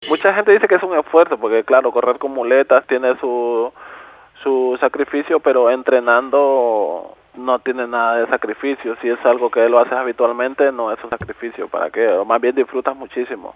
Más bien lo disfrutas muchísimo”, afirma con brillo de felicidad